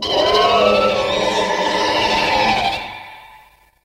File:GMK King Ghidorah Roar.ogg
GMK_King_Ghidorah_Roar.ogg